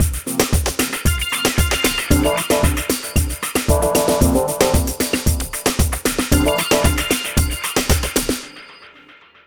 Ala Brzl 2 Full Mix 2b-C.wav